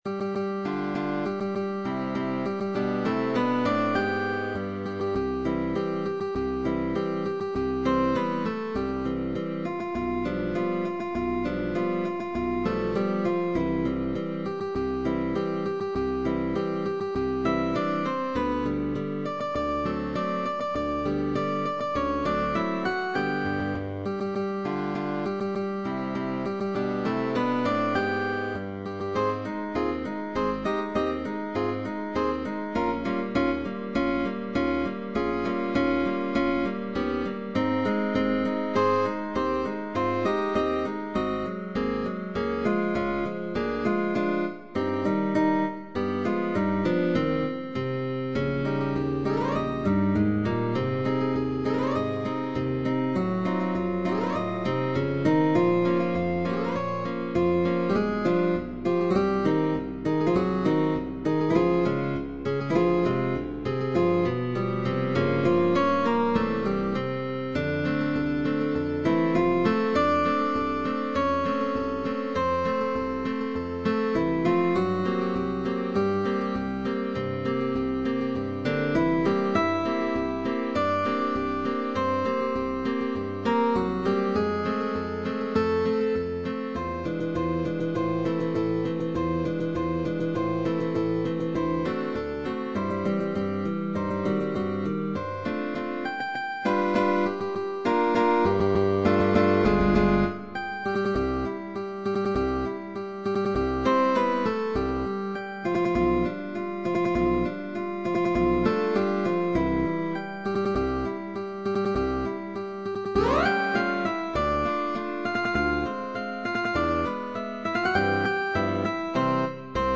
GUITAR QUARTET
Waltz in G minor Polka in C major Foxtrot in F-sharp minor